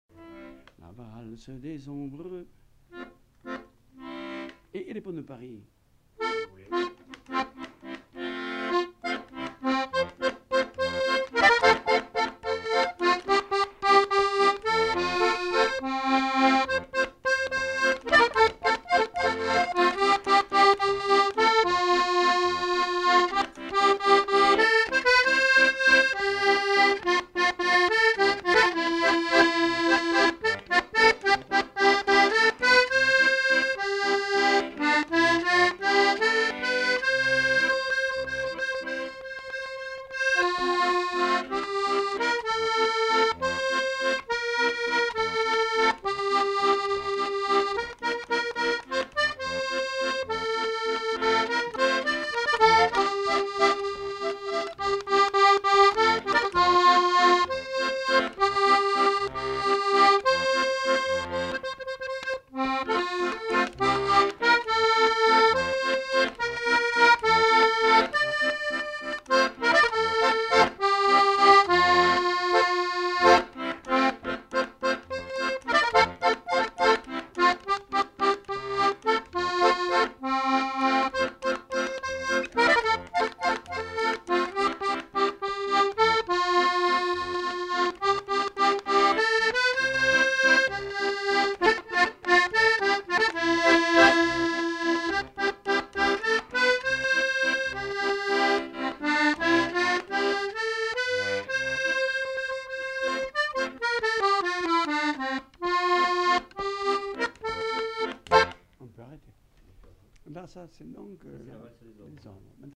Aire culturelle : Haut-Agenais
Lieu : Lougratte
Genre : morceau instrumental
Instrument de musique : accordéon diatonique
Danse : valse